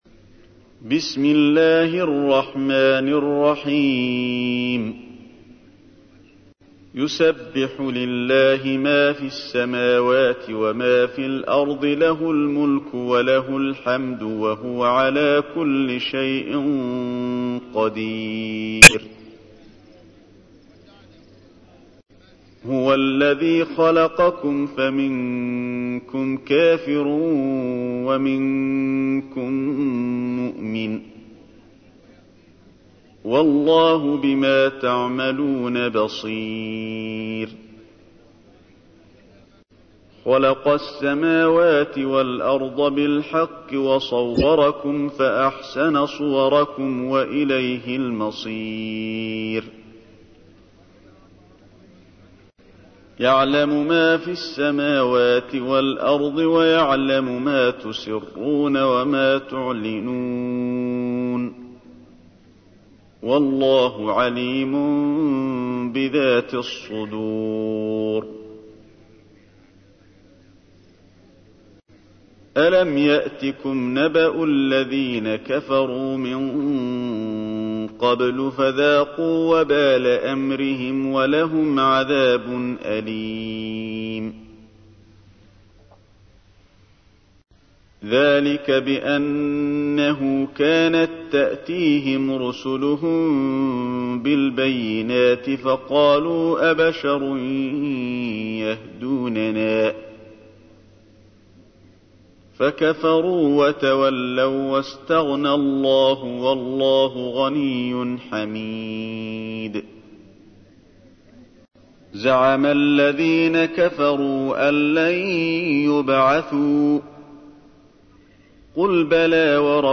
تحميل : 64. سورة التغابن / القارئ علي الحذيفي / القرآن الكريم / موقع يا حسين